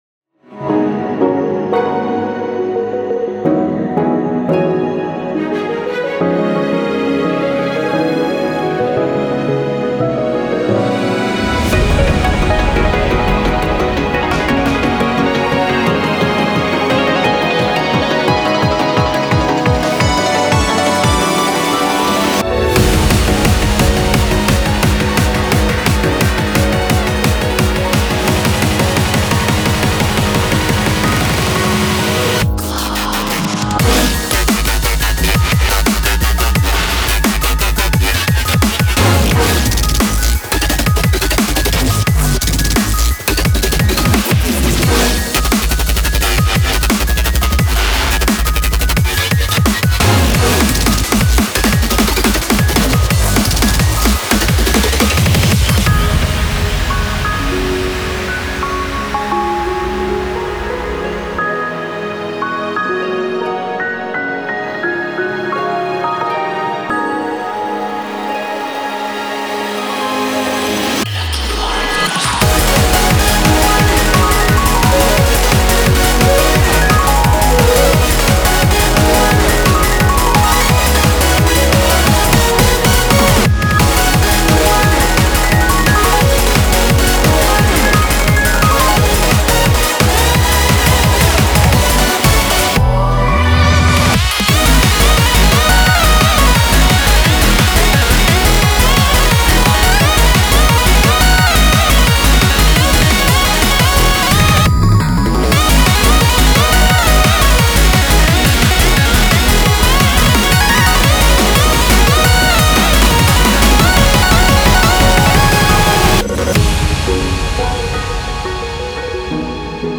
BPM44-174
MP3 QualityMusic Cut